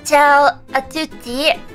Worms speechbanks
Hello.wav